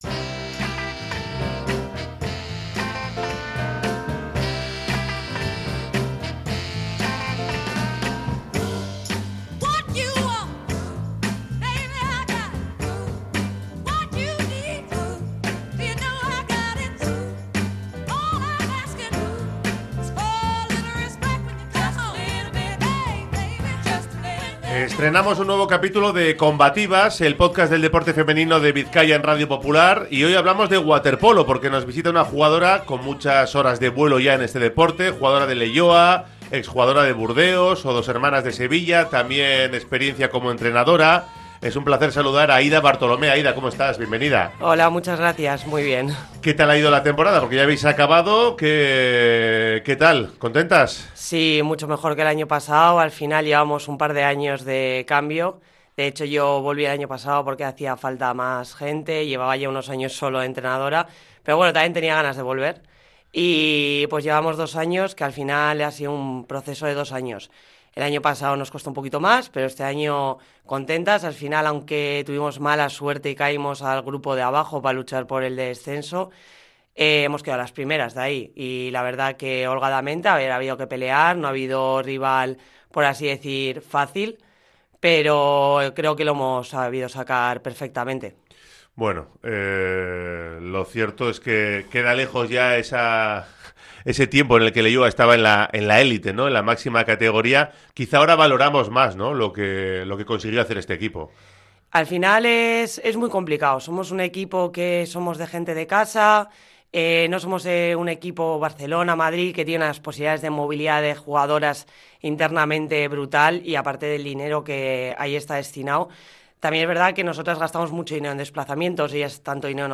Entrevista con la jugadora del Leioa Waterpolo